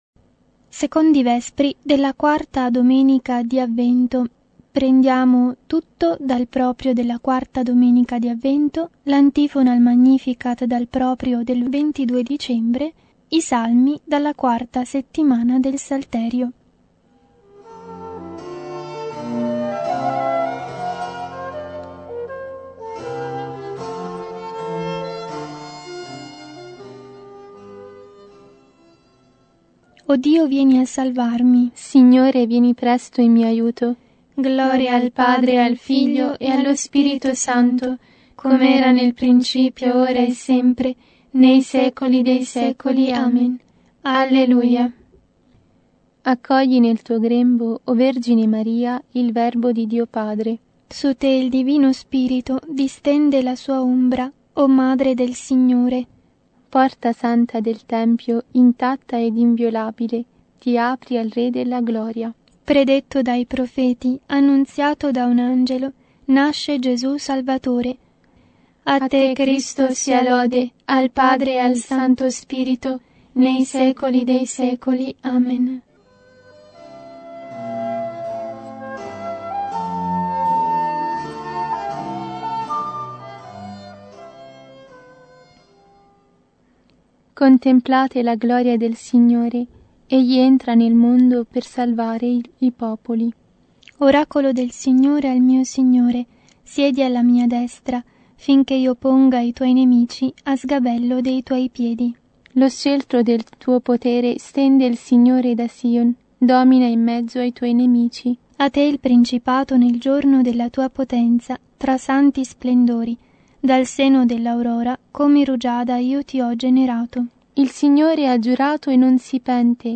Secondi Vespri della Quarta Domenica di Avvento